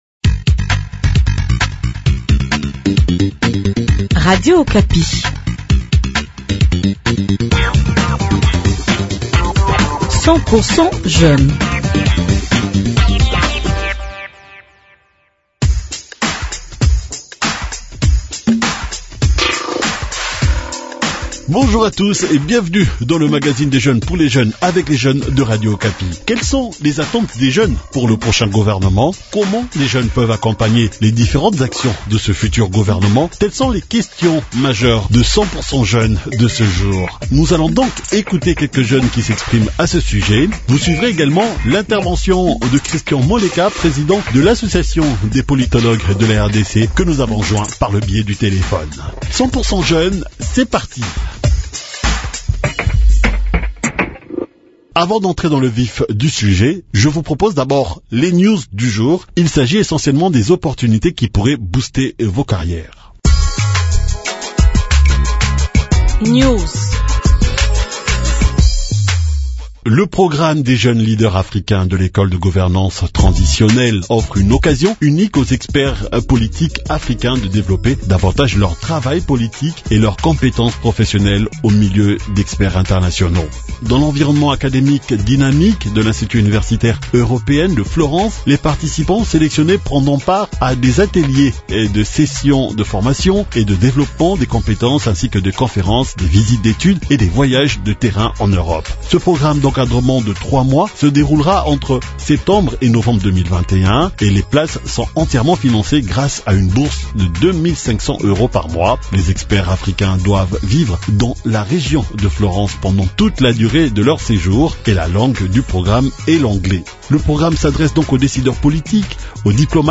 Nous allons écouter quelques jeunes qui s’expriment à ce propos.